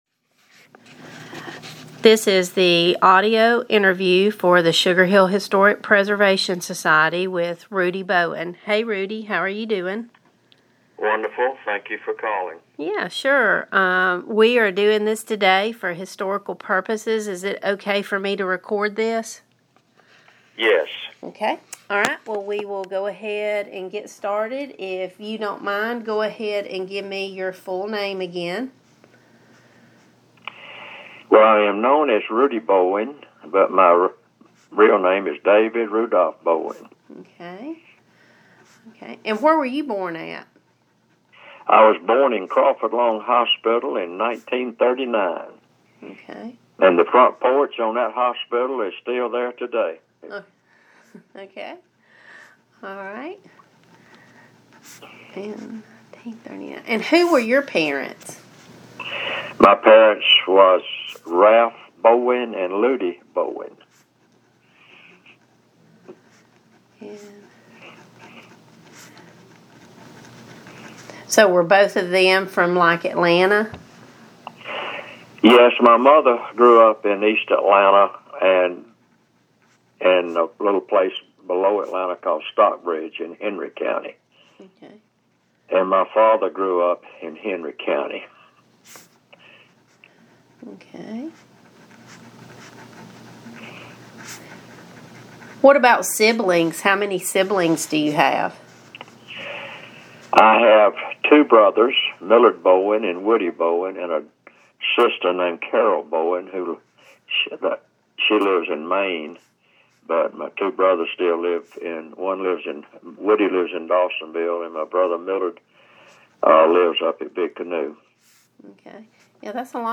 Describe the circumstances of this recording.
via telephone